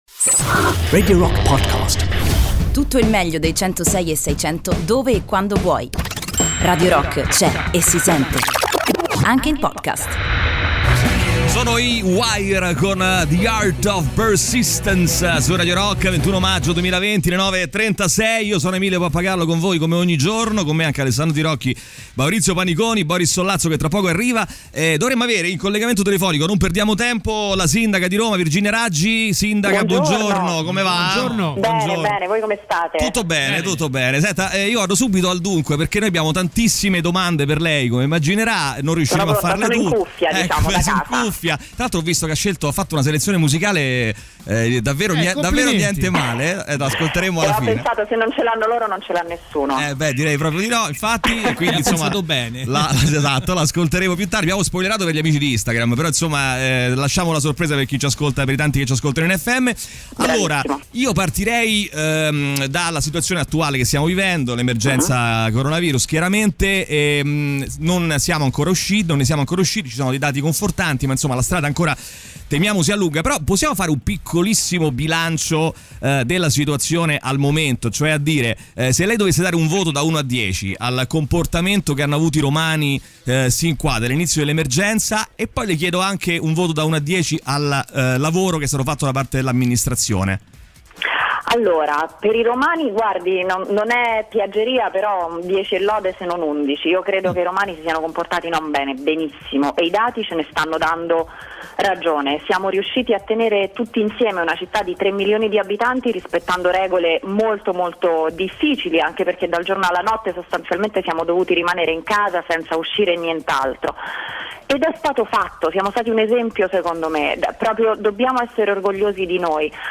Intervista: "Virginia Raggi" (21-05-20)
in collegamento telefonico con la sindaca di Roma Virginia Raggi durante il THE ROCK SHOW